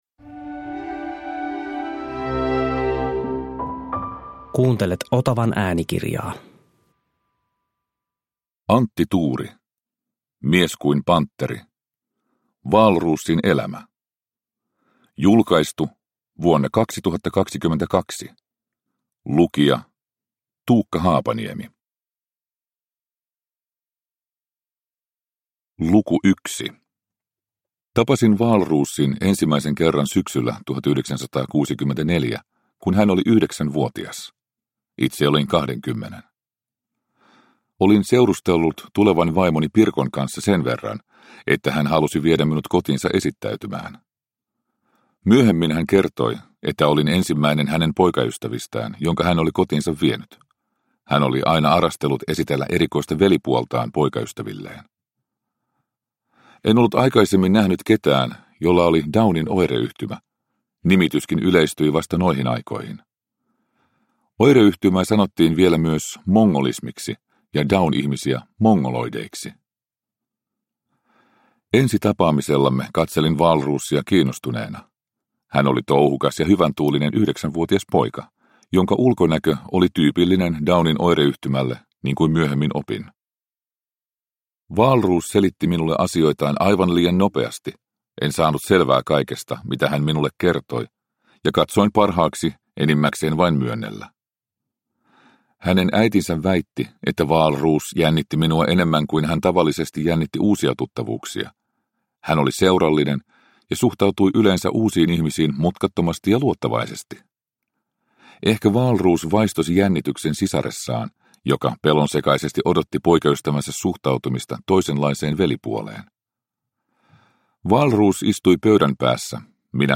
Mies kuin pantteri – Ljudbok – Laddas ner
Produkttyp: Digitala böcker